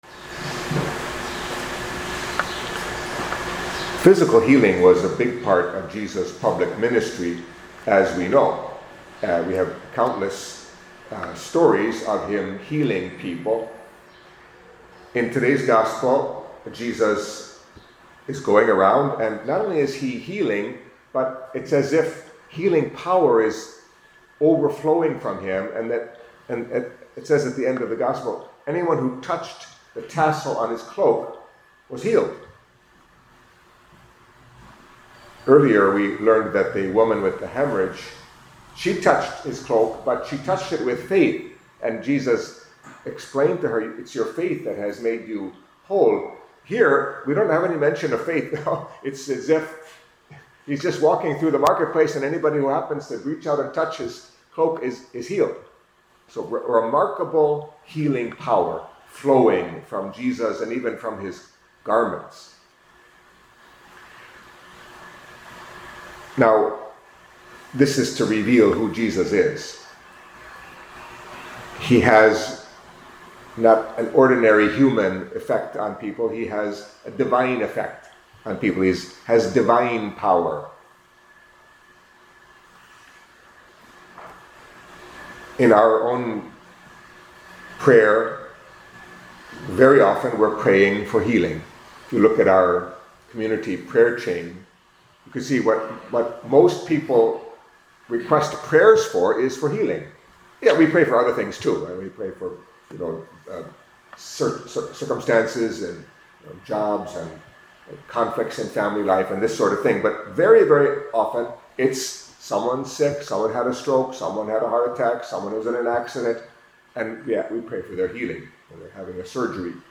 Catholic Mass homily for Monday of the Fifth Week in Ordinary Time